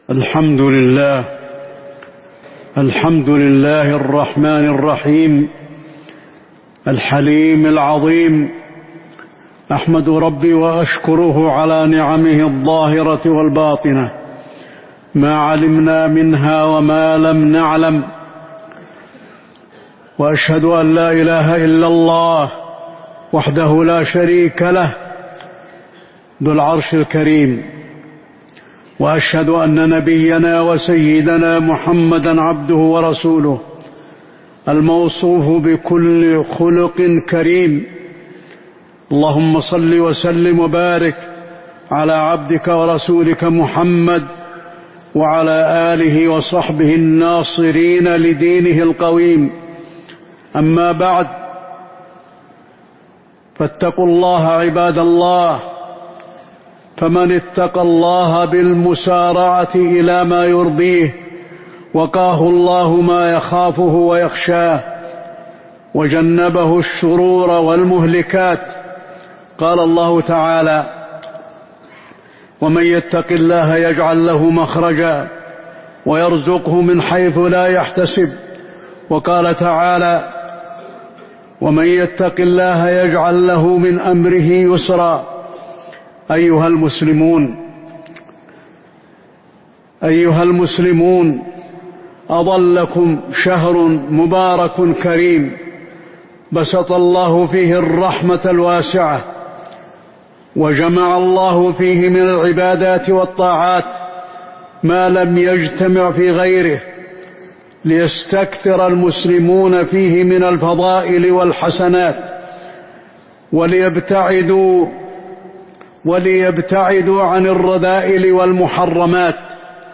تاريخ النشر ٢٠ شعبان ١٤٤٥ هـ المكان: المسجد النبوي الشيخ: فضيلة الشيخ د. علي بن عبدالرحمن الحذيفي فضيلة الشيخ د. علي بن عبدالرحمن الحذيفي شهر رمضان المبارك